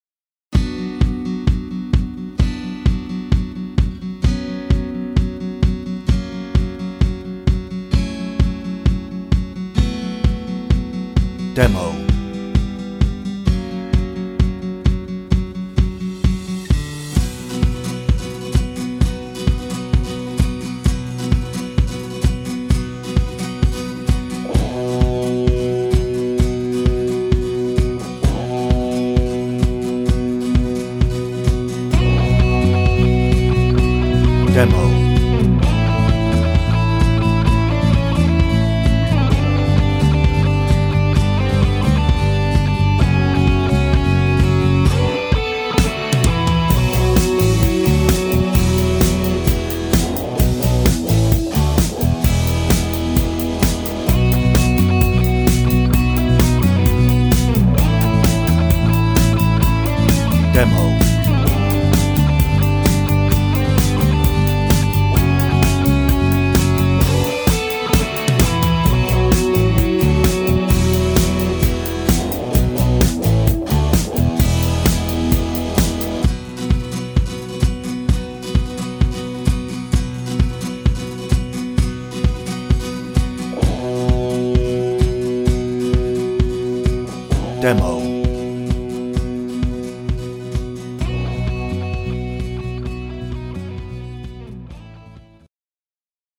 No ref vocal - with this release, you will get 3 versions
Instrumental